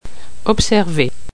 se prononce car le [s] sourd assimile le [b] en l'assourdissant comme un [p].